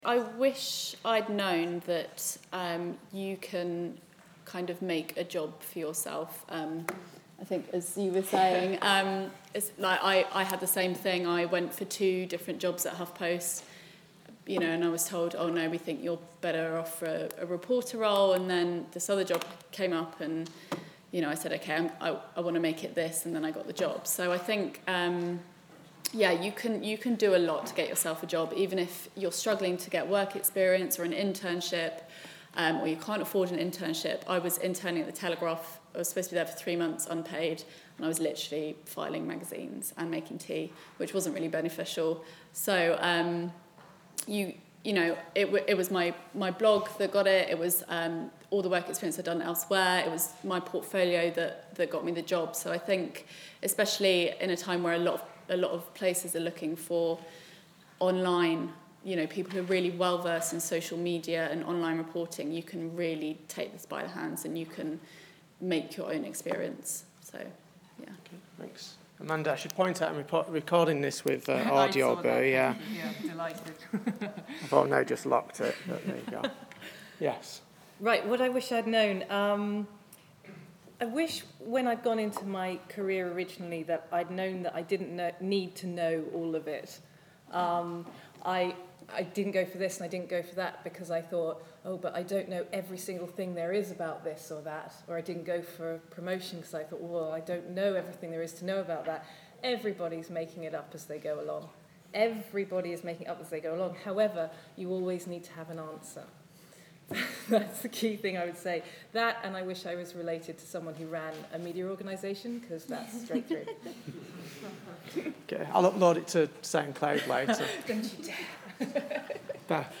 Industry panel: what I wished I knew